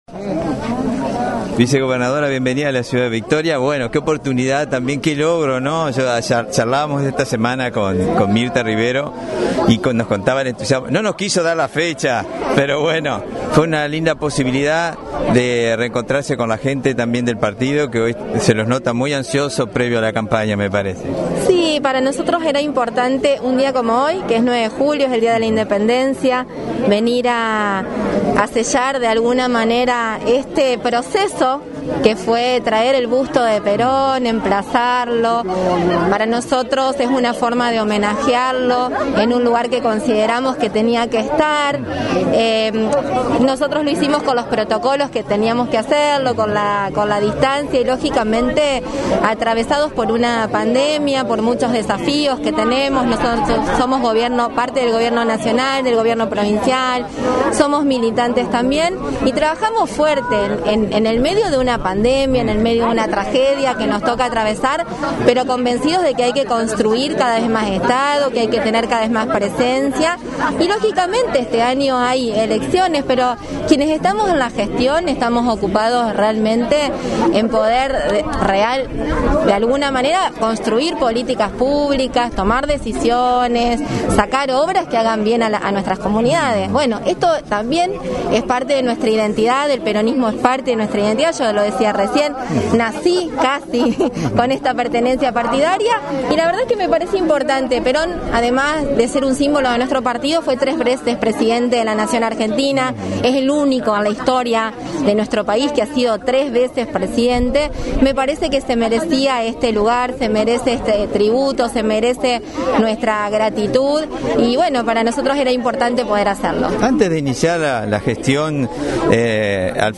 Al finalizar el acto, la vicegobernadora brindó unas palabras a este medio